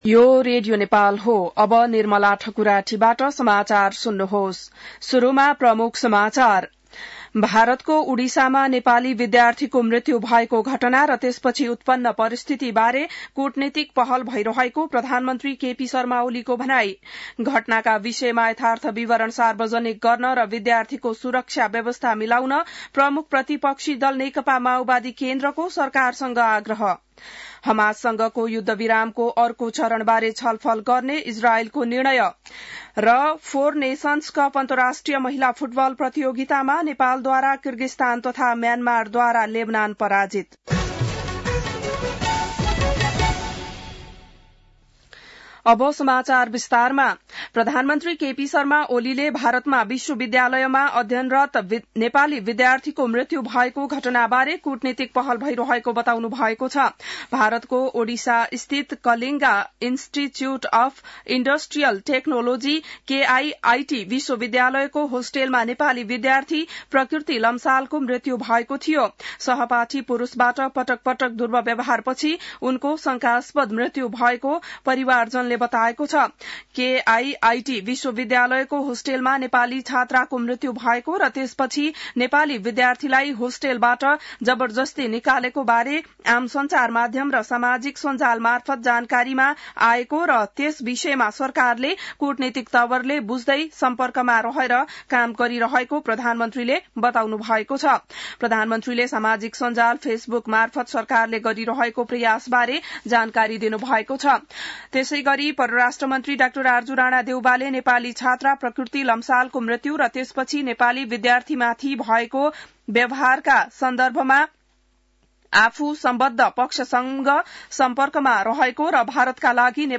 बिहान ९ बजेको नेपाली समाचार : ७ फागुन , २०८१